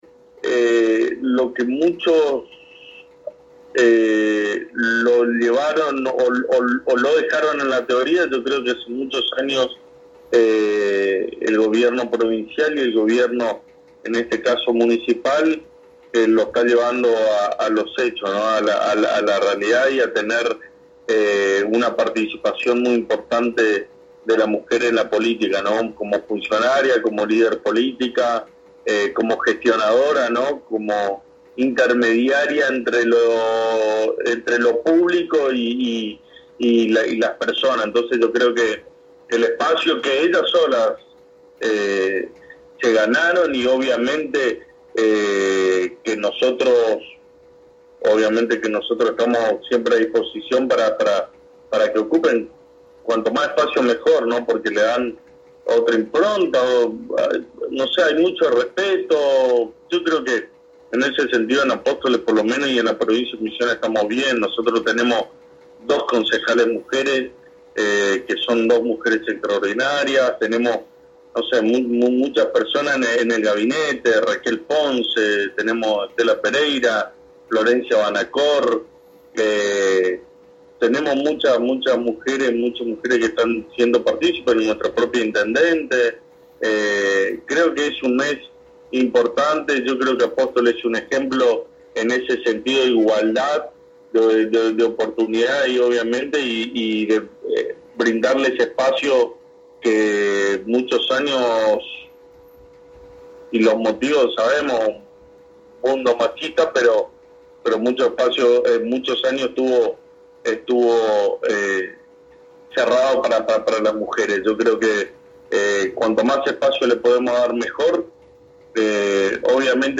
El Secretario de Gobierno de la Municipalidad de Apóstoles Gastón Casares comentó a la ANG en Éxito FM sobre las actividades que comienzan hoy en celebración del mes de la Mujer. Hoy lunes a partir de las 19 hs en la Casa del Mate se realiza la apertura de muestra artística «Mujeres en el Arte».